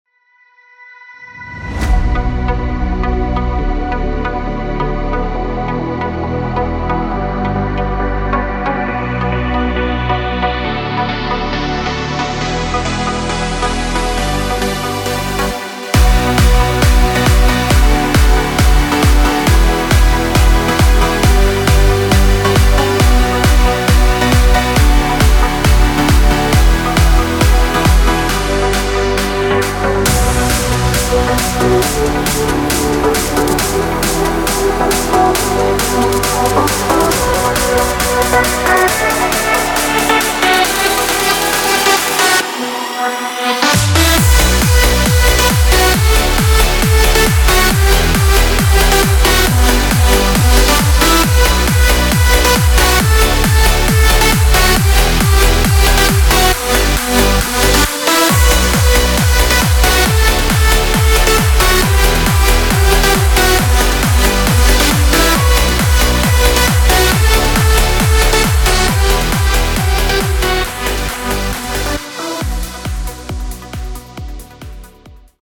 Popschlagersong im aktuellen Style.
Hier kannst du kurz ins Playback reinhören.
BPM – 136
Tonart – B-minor